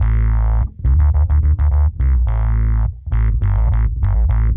Index of /musicradar/dub-designer-samples/105bpm/Bass
DD_PBassFX_105A.wav